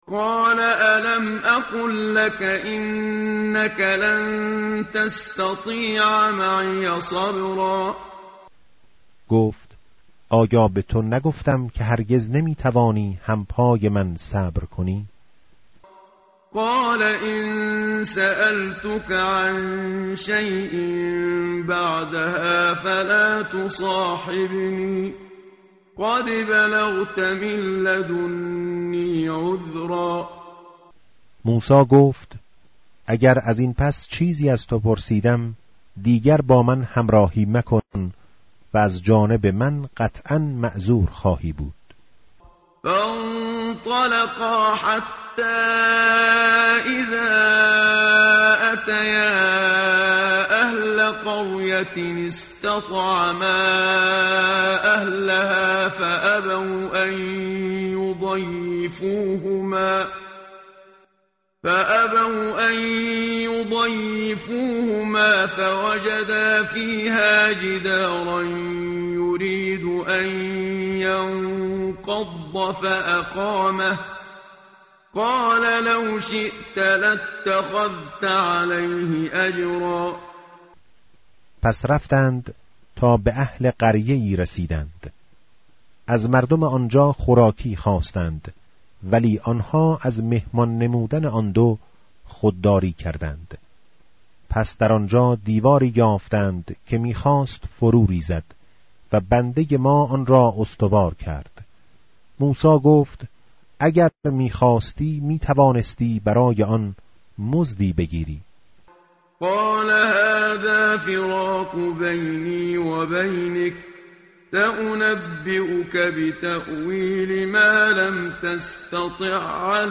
tartil_menshavi va tarjome_Page_302.mp3